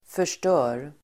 Uttal: [för_st'ö:r]